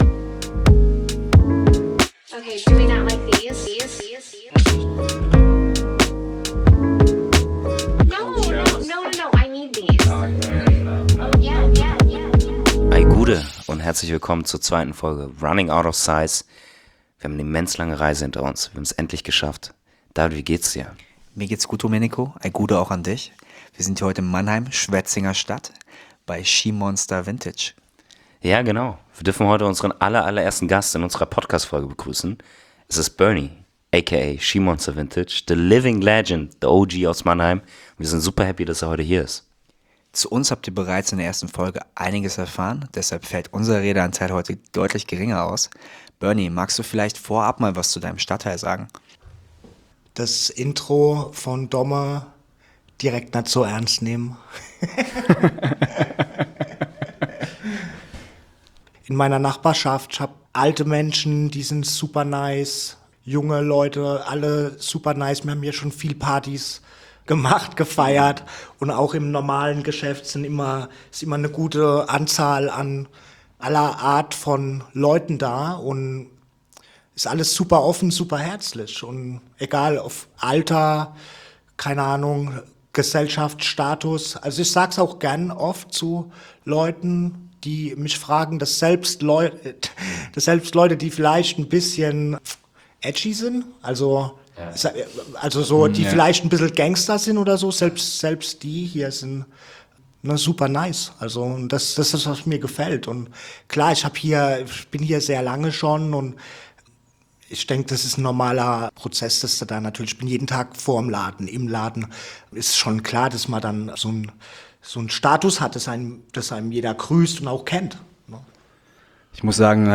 On the road! Off to Mannheim!“ … Wir haben unsere Sachen zusammengepackt und uns auf den Weg nach Mannheim gemacht.